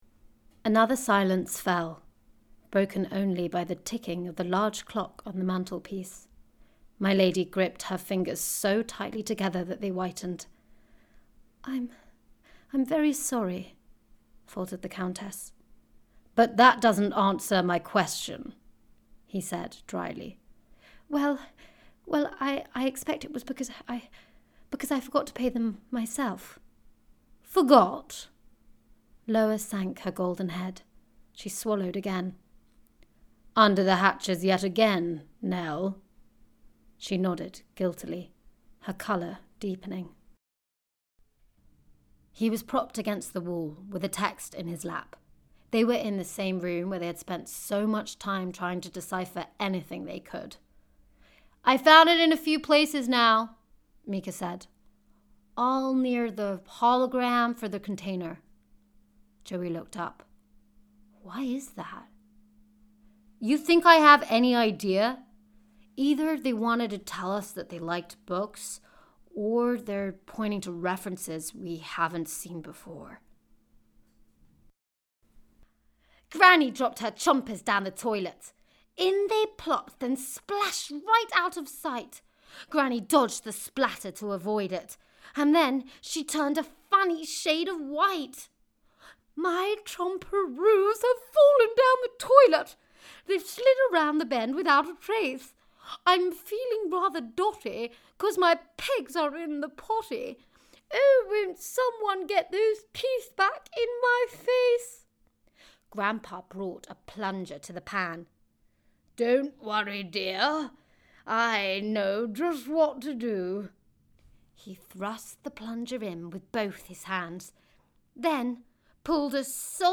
• Native Accent: RP